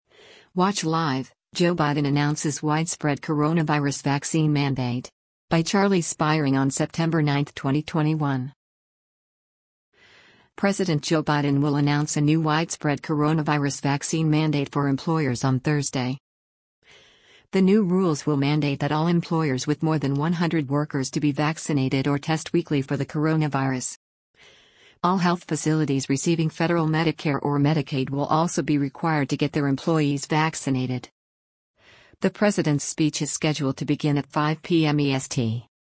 The president’s speech is scheduled to begin at 5:00 p.m. EST.